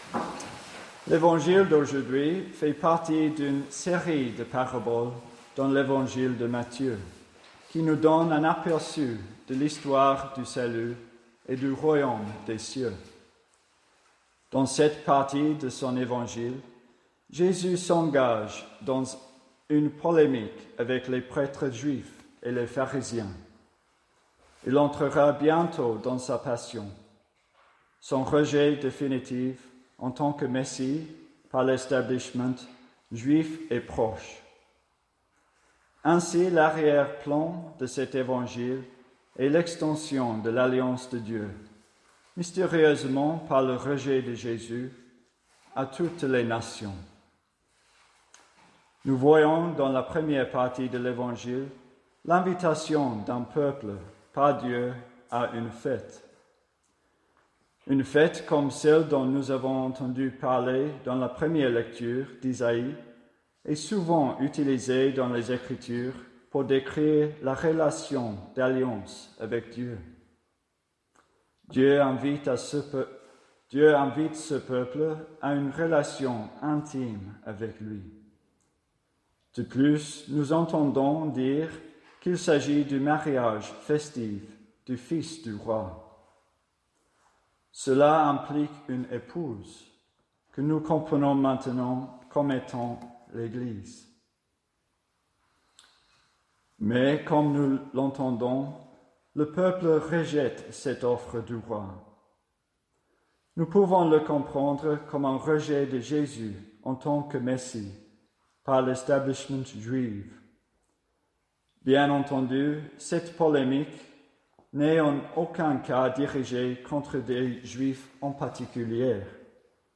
Pour ce 28ème dimanche du Temps Ordinaire
Grâce à un enregistrement réalisé en direct dans la chapelle,